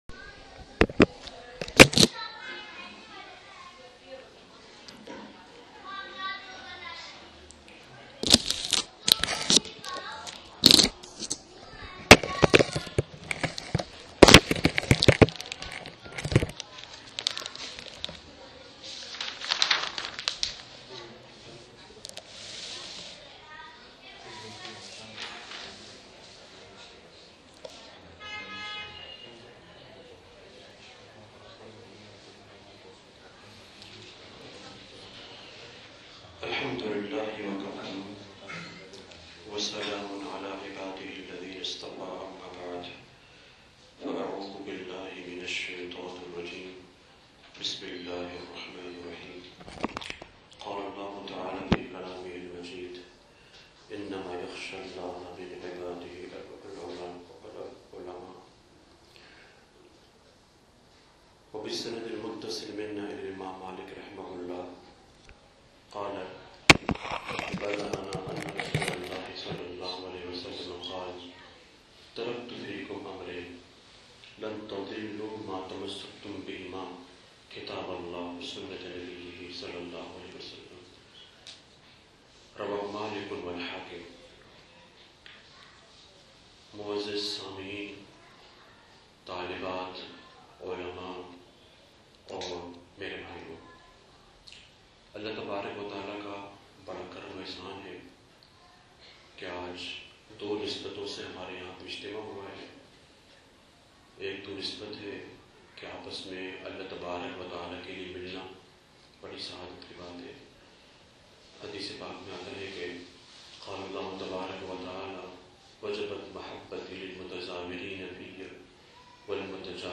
The most awaited event of Khatam e Bukhari, which was held on February 4th, Saturday, to celebrate the completion of Sahih Al-Bukhari & of six years of advanced Islamic study by the Alimahs of the graduating class. This blessed program began with the women’s program by the students reciting the last few verses of Surah Hashar, followed by a melodious nasheed, a kalam, and finally an inspirational bayan by the respected speaker.